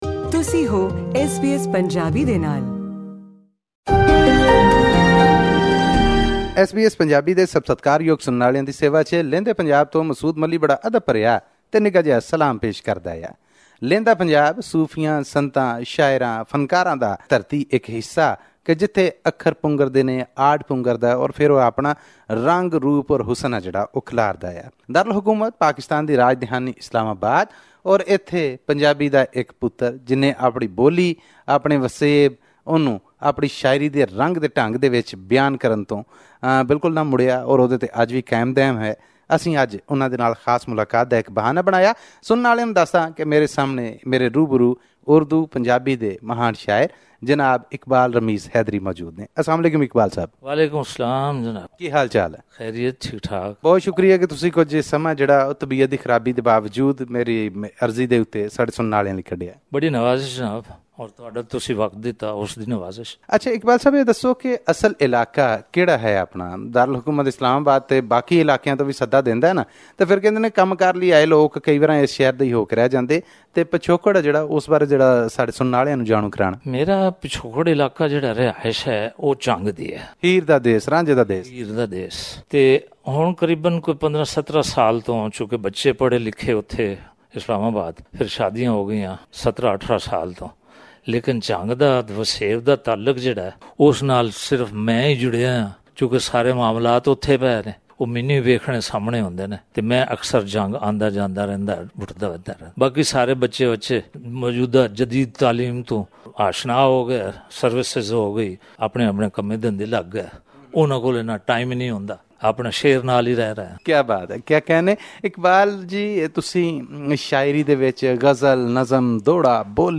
Listen to the full story in today's cultural report from Pakistan.